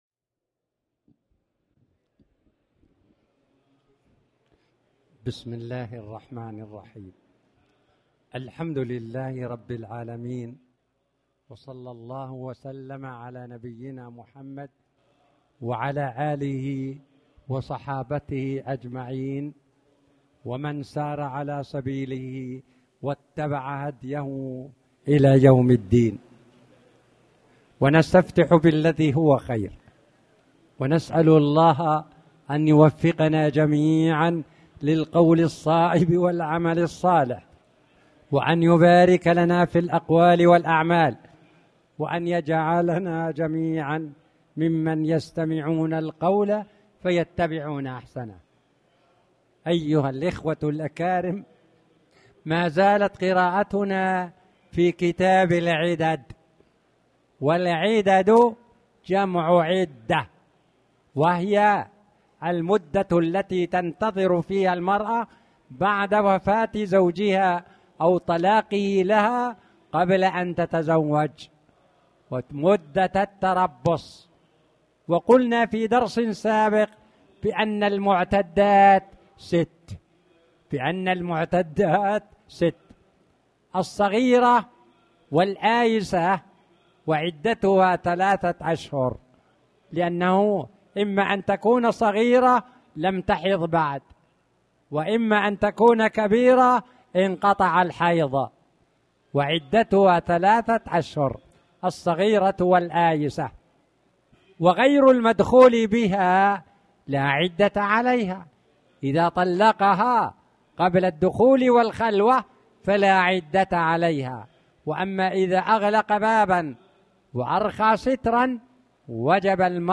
تاريخ النشر ٢ صفر ١٤٣٩ هـ المكان: المسجد الحرام الشيخ